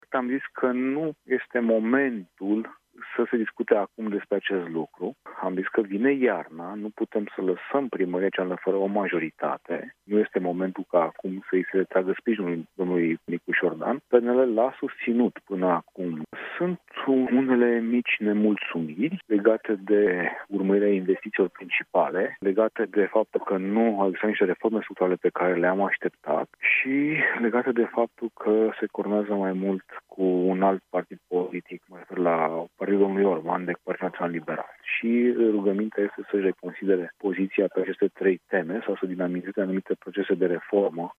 Nu este momentul, spune la Europa FM, Ciprian Ciucu, președintele PNL București.